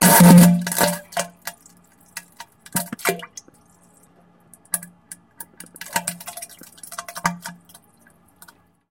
Звуки поноса
Звук поноса в унитазе